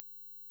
fm_5sin_192khz.wav